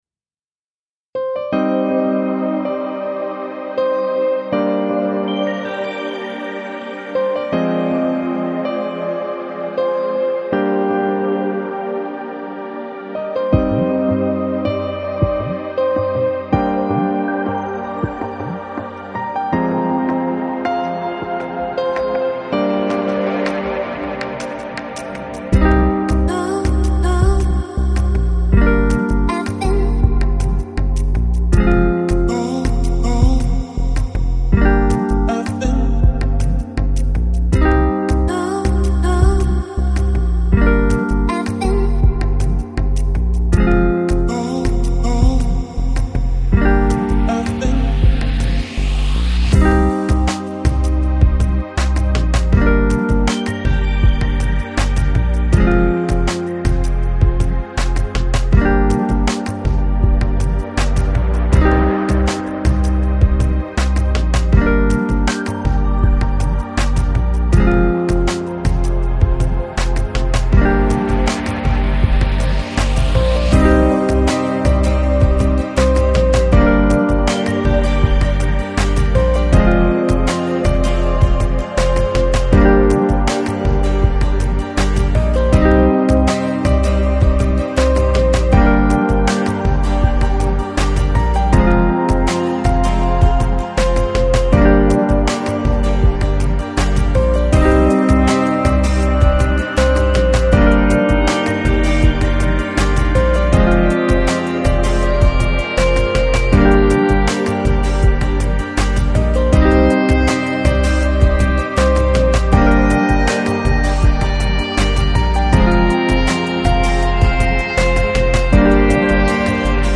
Electronic, Downtempo, Lounge, Chill Out, Balearic